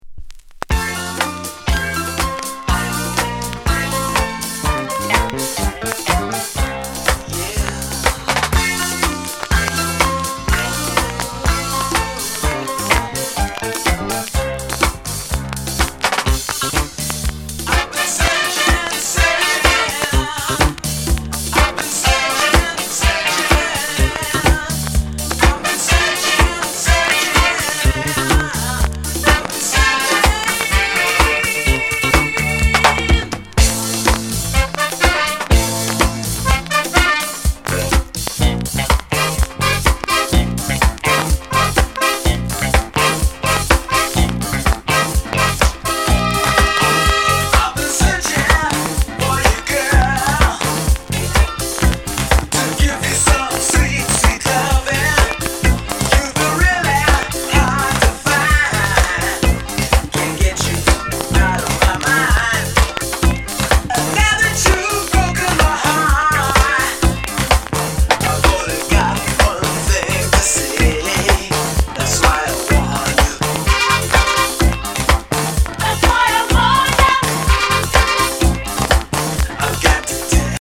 Genre:  Soul/Reggae